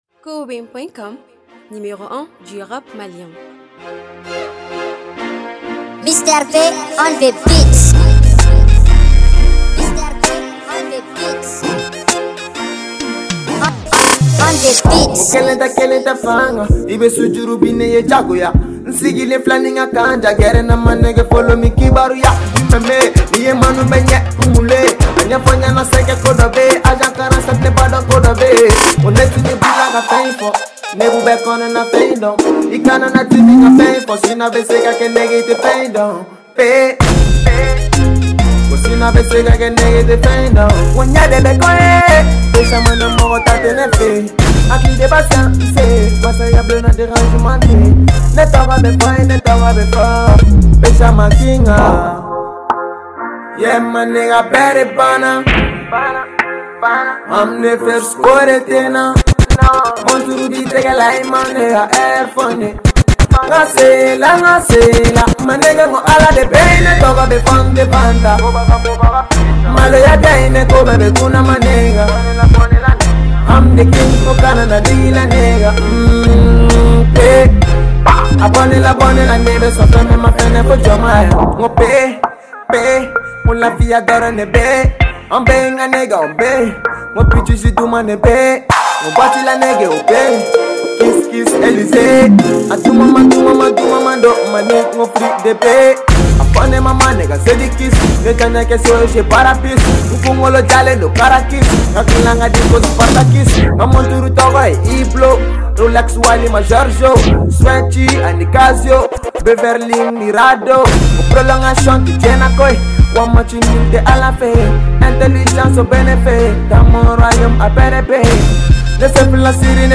egotrip
musique Mali afro-trap.